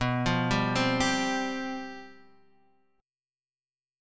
Csus/B chord